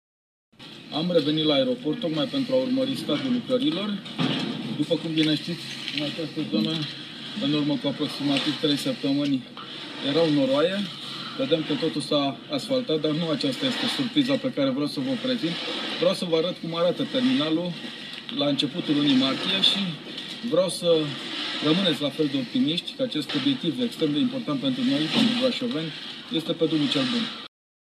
La aproape un an de la momentul începerii lucrărilor, construcţia este terminată, iar finisajele interioare sunt în stadiu final de execuţie, a anunțat ieri, chiar de pe șantier, președintele Consiliului Judetean Brașov, Adrian Veștea: